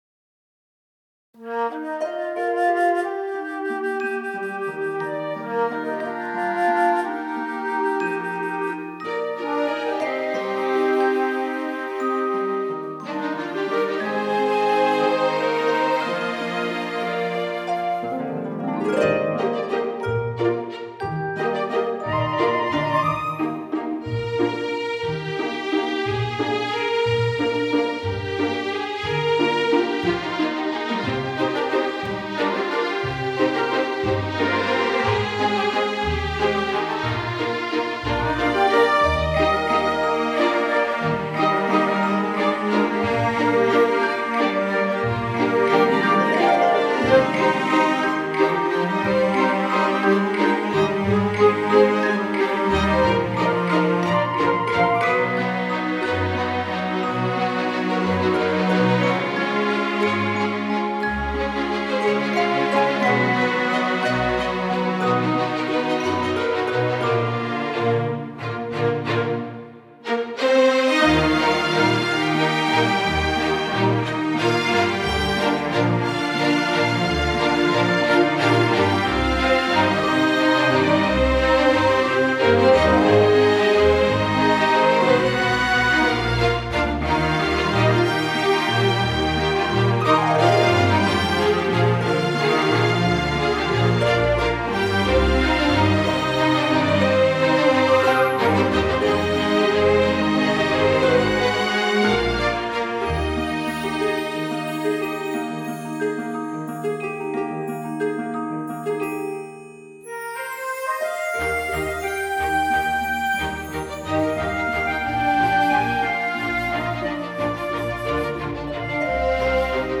现实·家庭·温情
主题原声
配乐试听